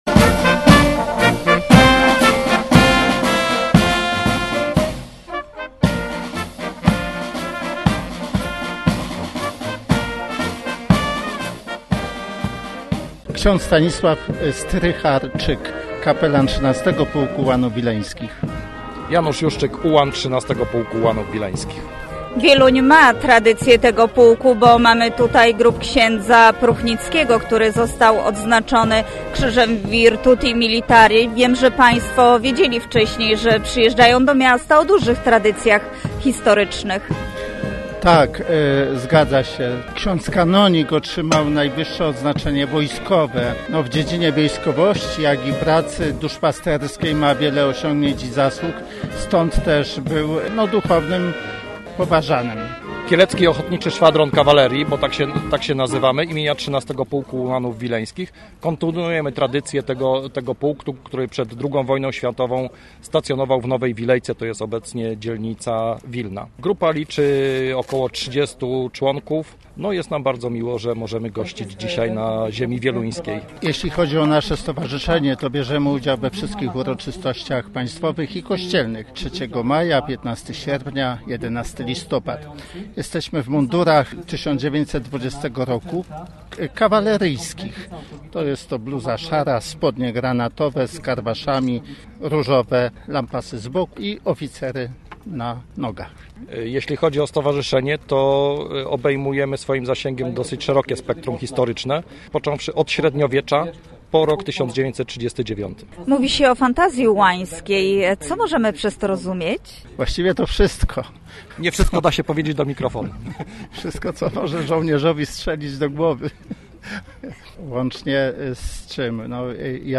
Na plantach przed Muzeum Ziemi Wieluńskiej stanęło miasteczko przypominające wydarzenia sprzed 100 lat. Niestety rzęsisty deszcz przeszkodził w pełnym uczestnictwie w tym ciekawym wydarzeniu.